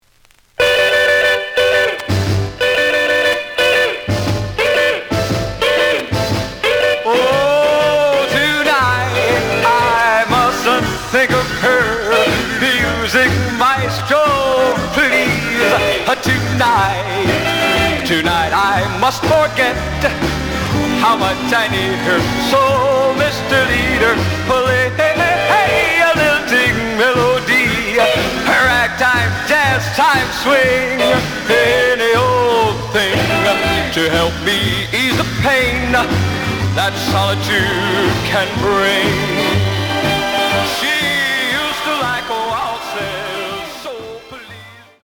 The audio sample is recorded from the actual item.
●Genre: Rhythm And Blues / Rock 'n' Roll
Some periodic noise on B side.)